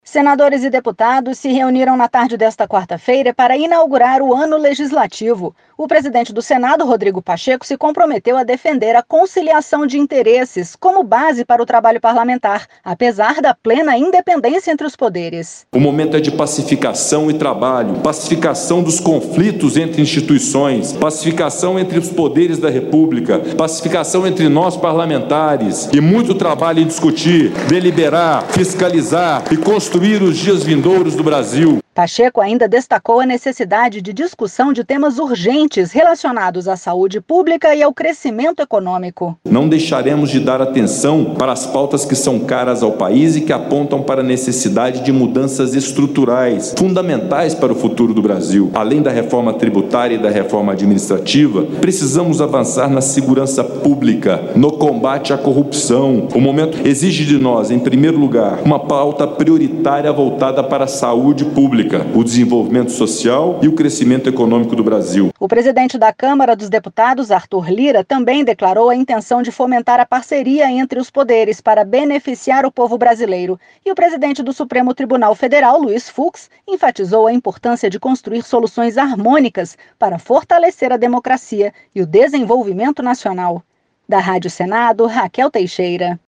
Durante a abertura do ano legislativo, nesta quarta-feira (3), o presidente do Senado, Rodrigo Pacheco, afirmou que a meta do Congresso Nacional é avançar com reformas estruturantes em 2021, sem deixar de lado a saúde pública.